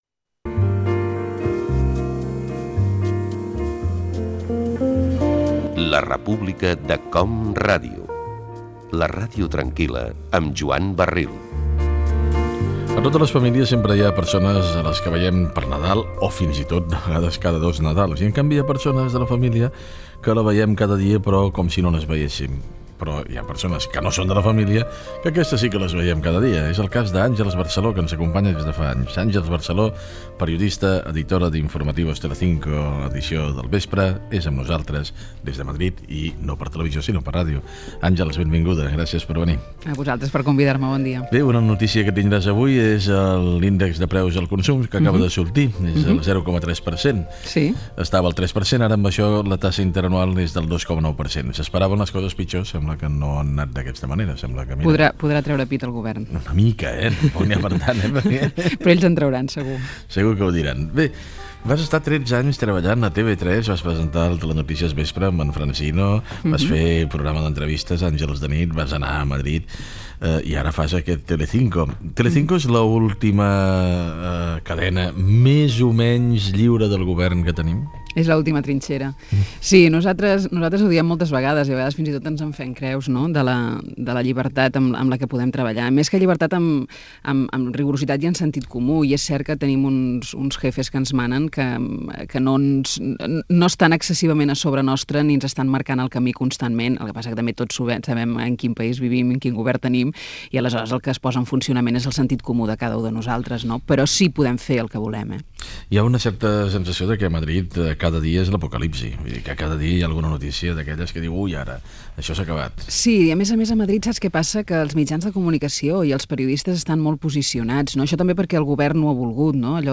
Indicatiu del programa, entrevista a la periodista Àngels Barceló, aleshores presentadora i editora de l'informatiu del vespre a Tele 5, sobre la seva feina periodística i el grau de llibertat que té
Info-entreteniment